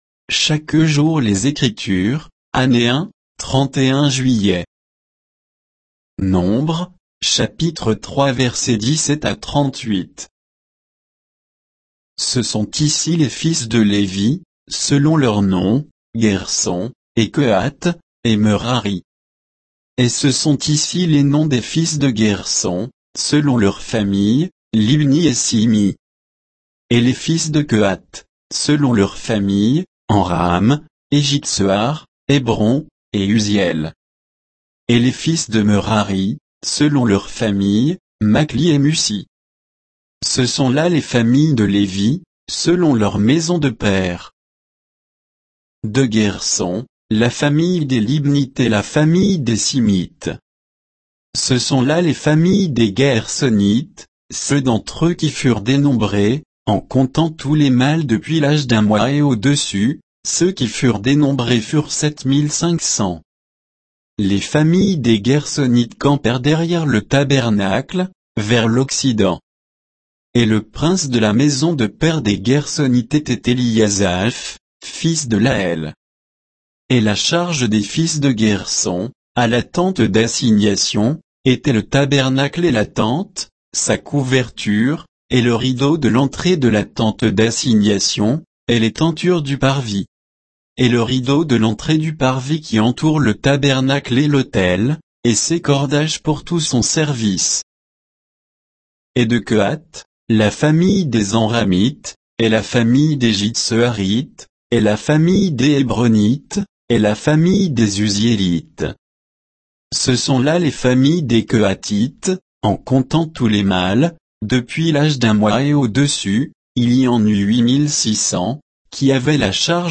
Méditation quoditienne de Chaque jour les Écritures sur Nombres 3, 17 à 38